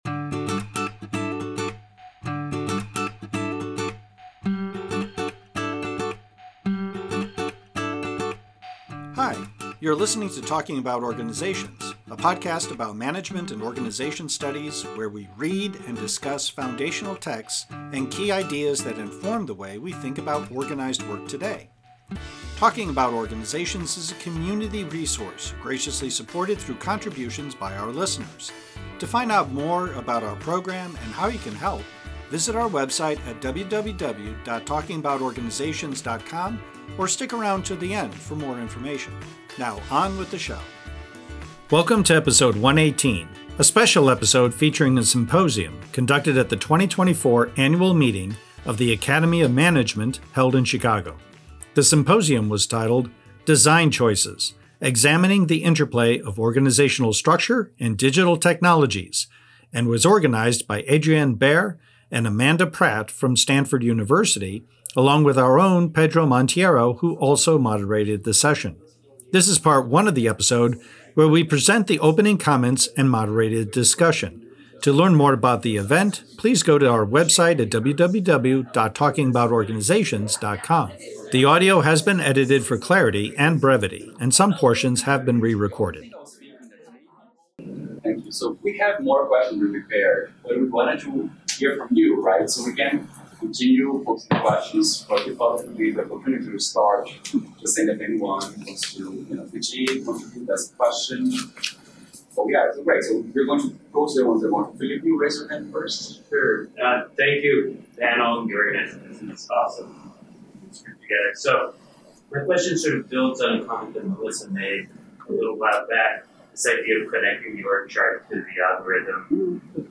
Covers a panel symposium on the interplay of organizational structures & digital tech presented at AoM 2024
There were a total of four presentations, of which one was virtual.